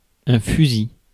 Ääntäminen
IPA: [fy.zi]